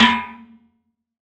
6TIMBALE L1R.wav